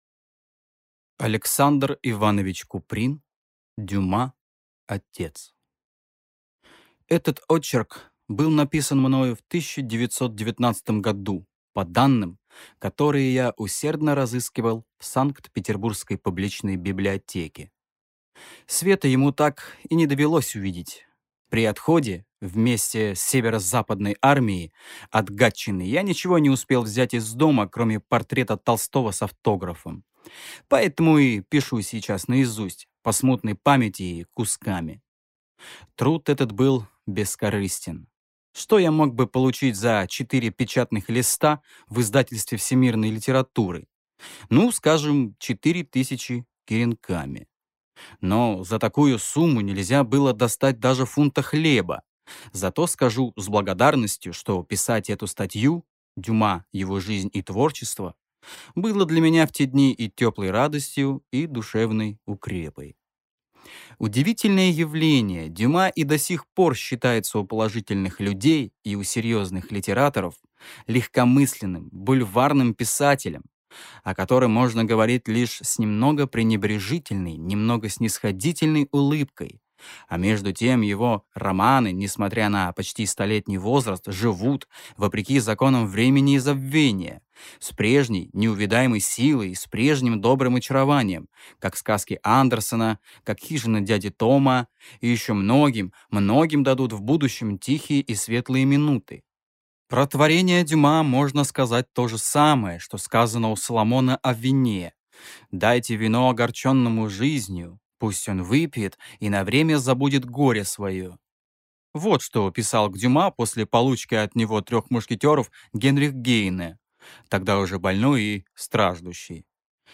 Аудиокнига Дюма-отец | Библиотека аудиокниг